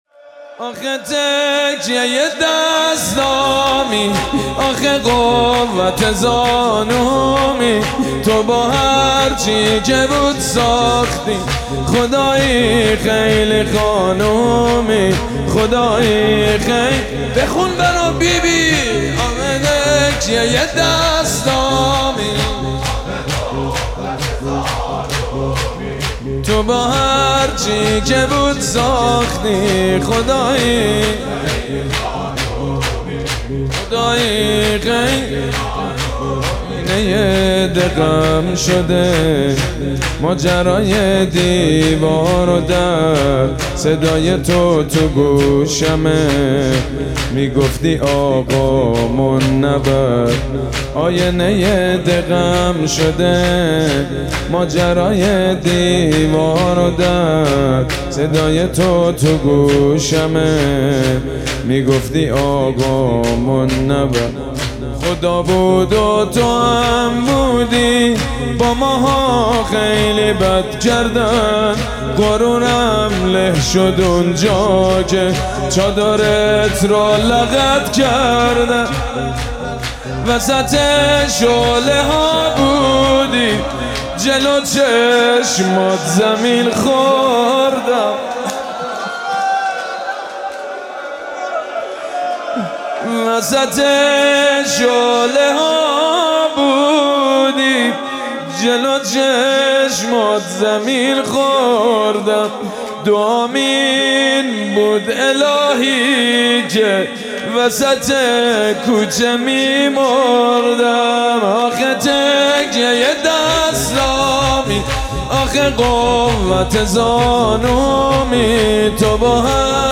مداحی و نوحه
سینه زنی، شهادت حضرت زهرا(س